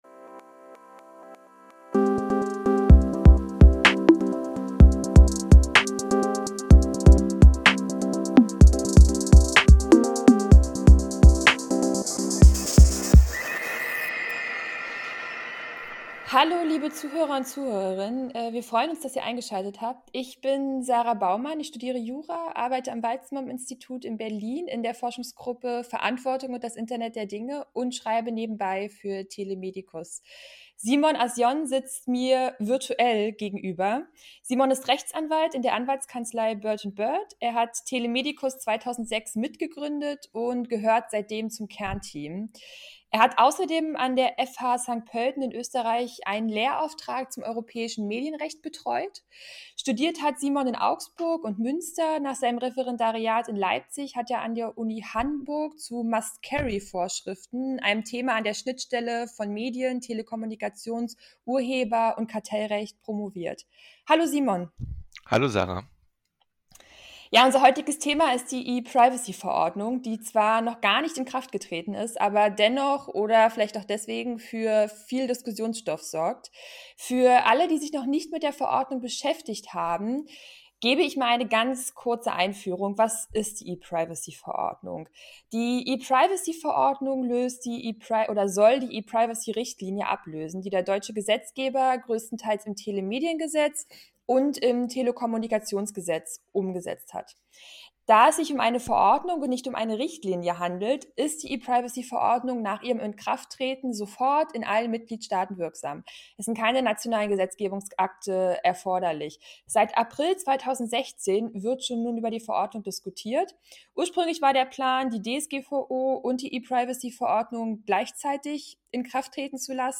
Podcast-e-Privacy-inkl.-Intro-Outro.mp3